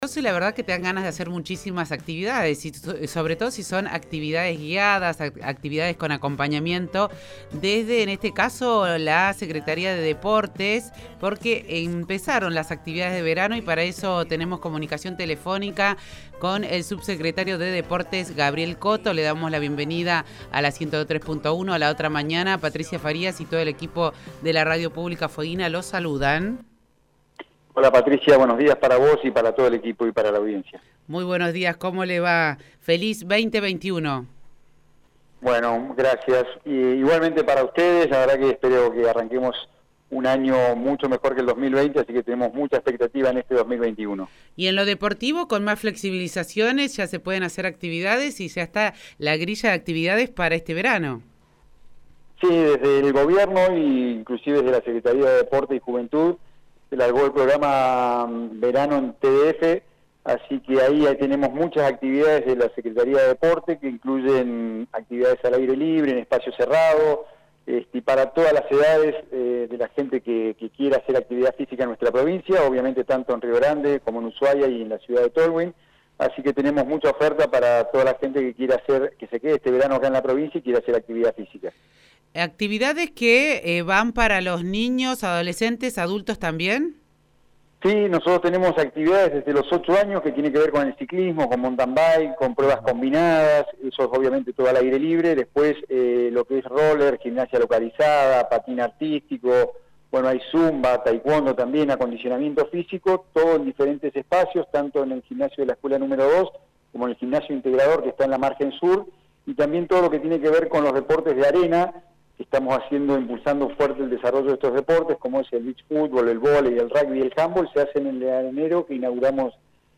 El Subsecretario de Deportes, Gabriel Coto, habló en FM103.1 sobre las actividades y propuestas deportivas para este verano 2021 respetando los protocolos correspondientes.